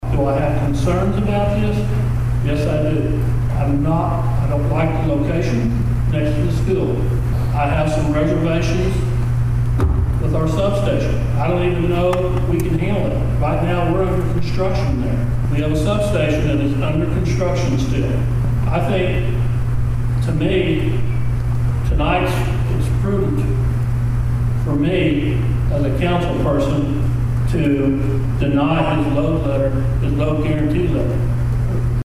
At Tuesday's evening's city council meeting in Pawhuska, council members had the option to provide a letter to Buckley Bros. Holdings, LLC showing they wanted to move forward with the data center.